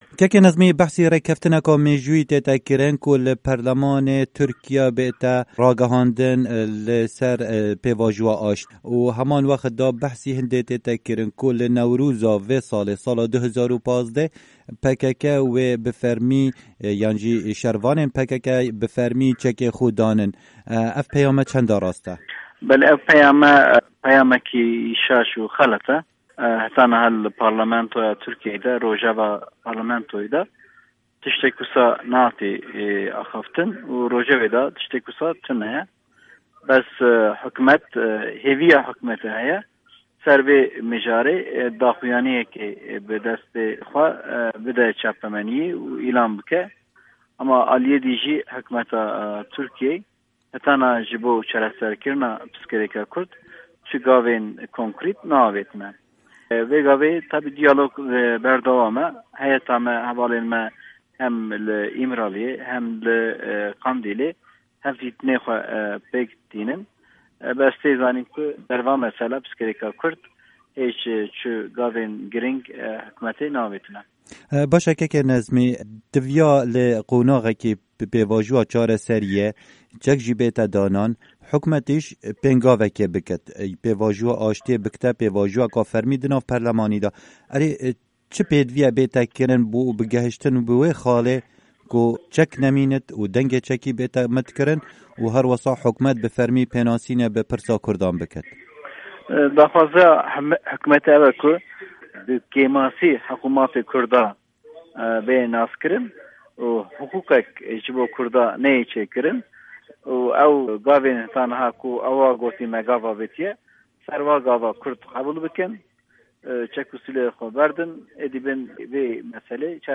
Hevpeyvin digel Nazmî Gur